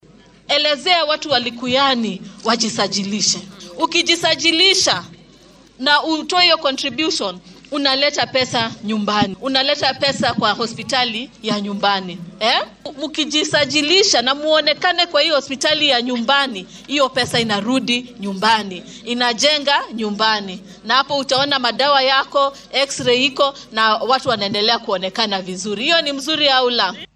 Xilli ay hoggaaminaysay munaasabad wacyigelin ah oo lagu qabtay dugsiga Moi’s Bridge ee deegaanka Likuyani ee ismaamulka Kakamega ayay wasiir Barasa tilmaantay in caymiskan uu faa’iidooyin badan u leeyahay shacabka.